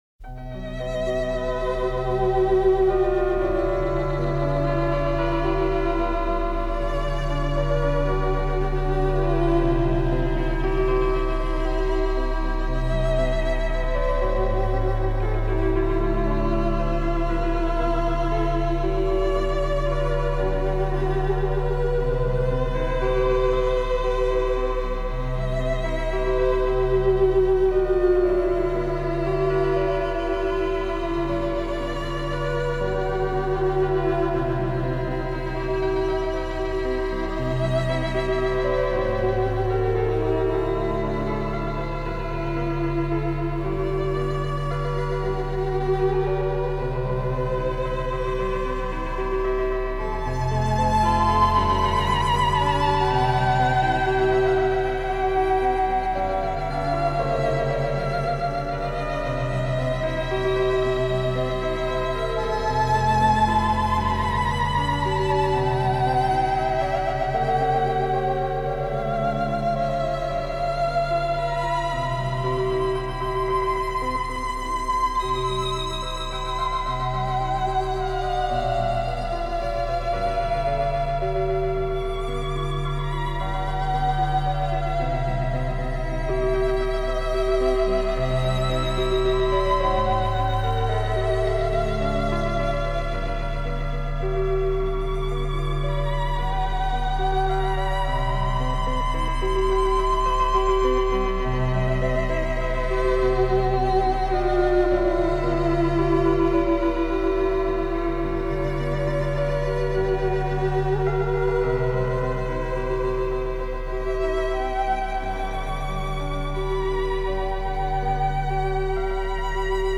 today’s instrumental selection